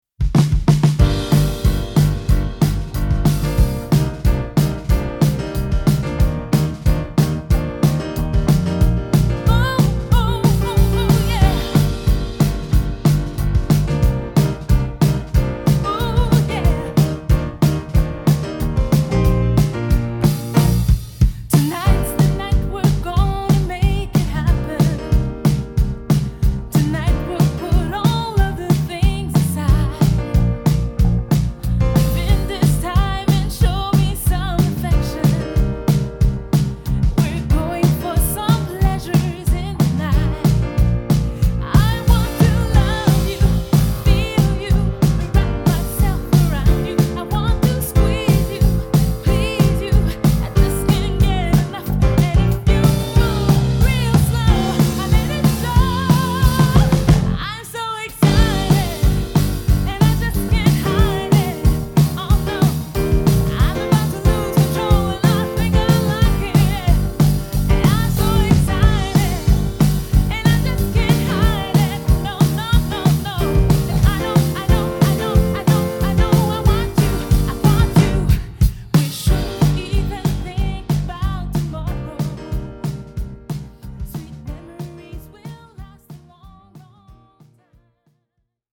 Quartett